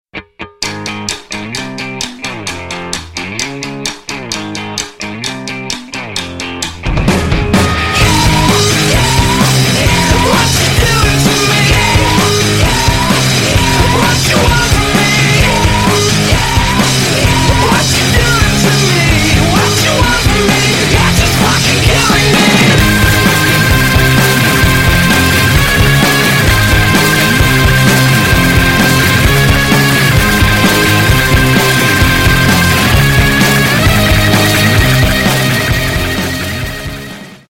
Громкие Рингтоны С Басами
Рок Металл Рингтоны